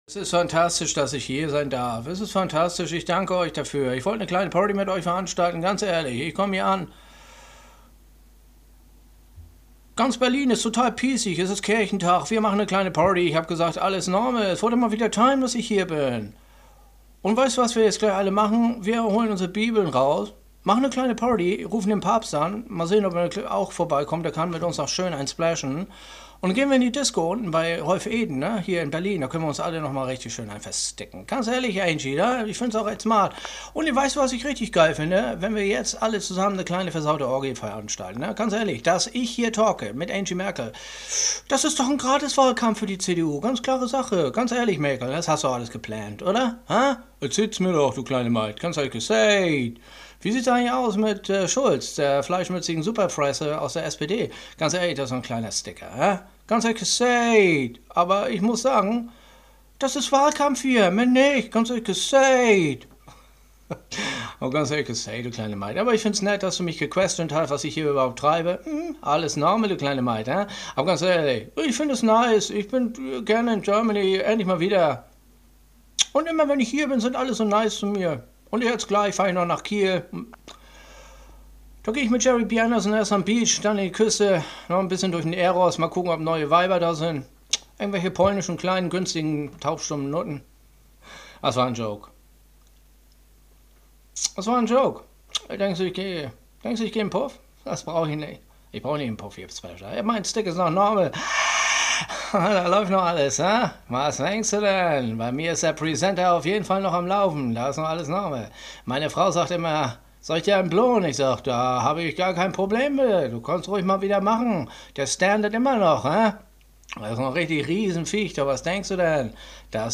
OBAMA SCHLÄGT ZURÜCK - Kirchentag Berlin Synchro (128kbit_AAC).m4a